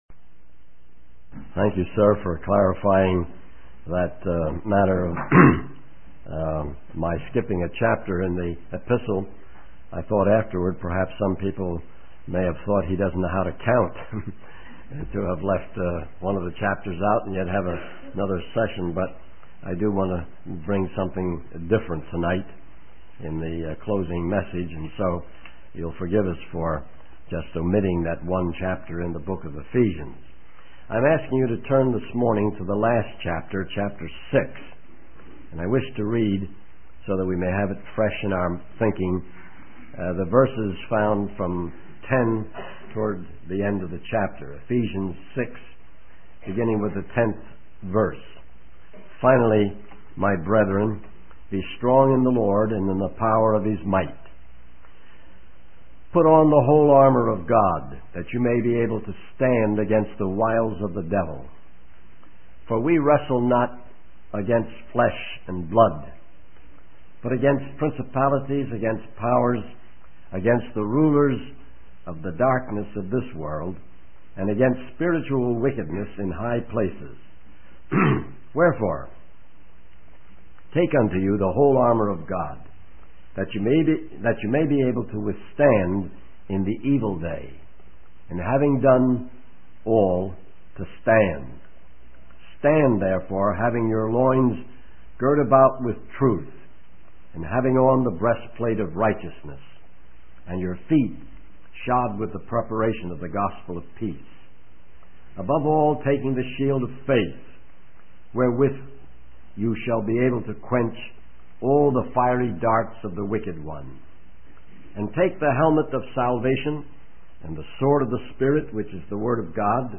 In this sermon, the speaker reflects on the concept of fairness and the challenges of preaching the word of God.